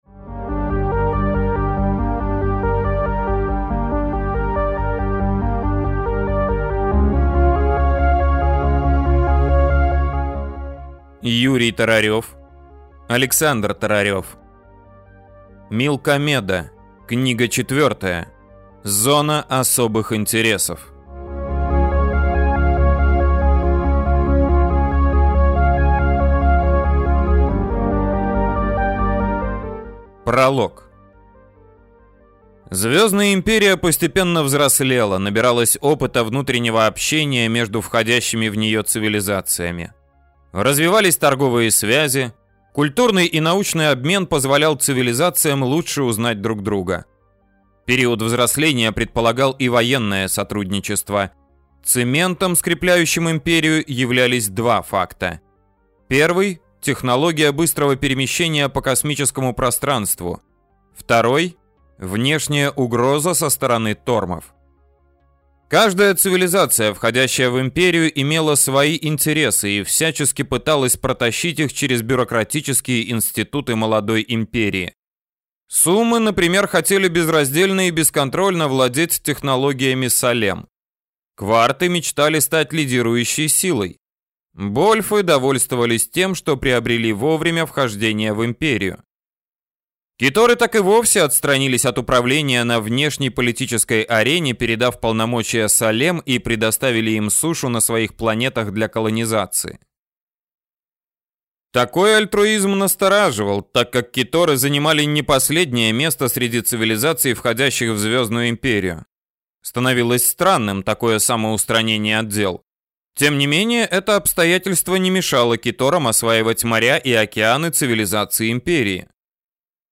Аудиокнига Милкомеда. Книга 4. Зона особых интересов | Библиотека аудиокниг